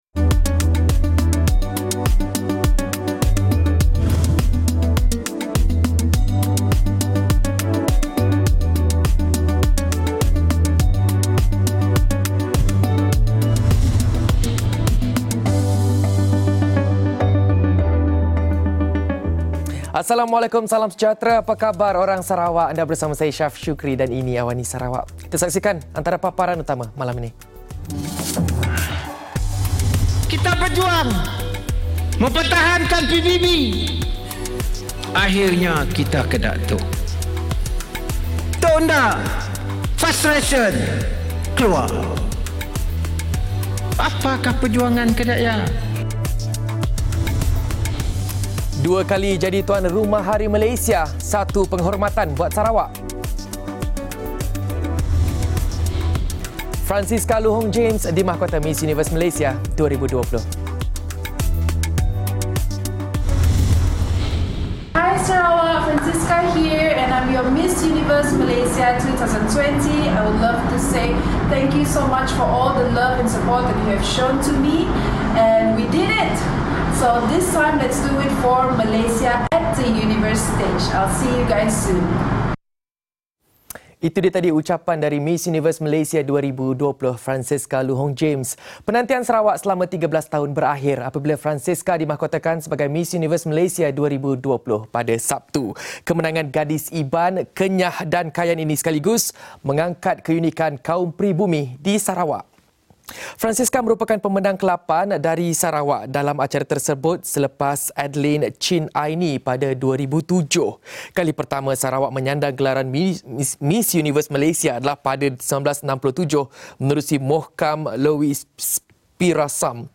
Laporan berita padat dan ringkas dari Bumi Kenyalang hari ini